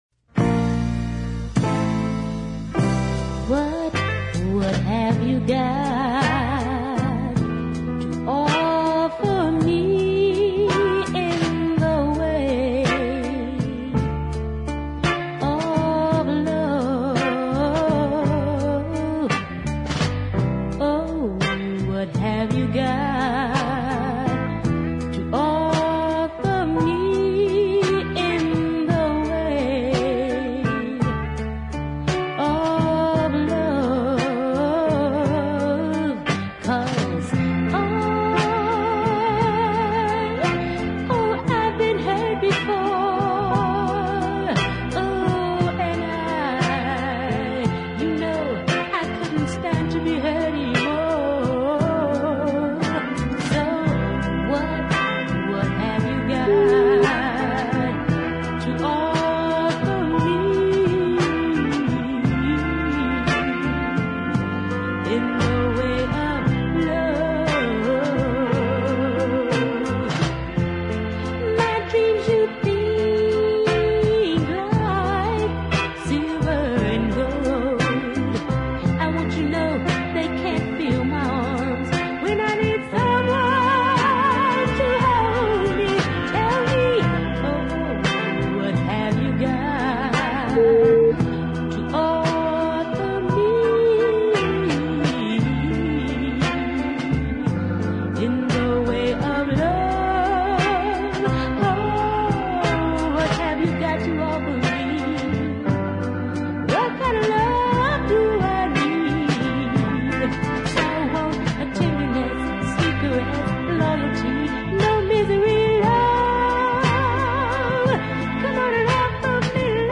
rather gentle vocals